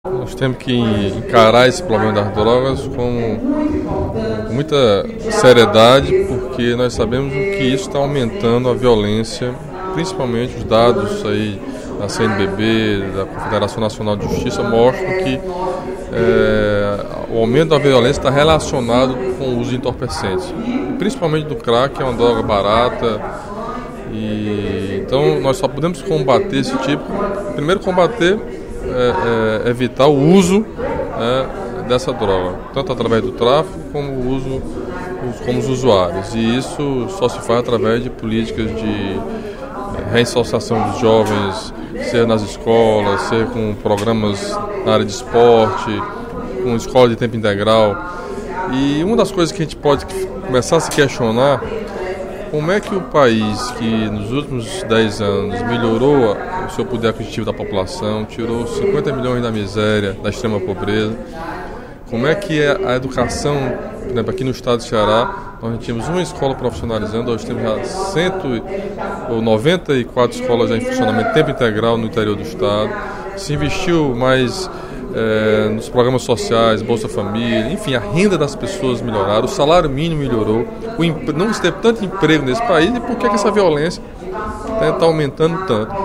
Durante o primeiro expediente da sessão plenária desta quinta-feira (14/11), o deputado Camilo Santana (PT) falou sobre as políticas públicas no combate às drogas e a relação com o aumento da violência.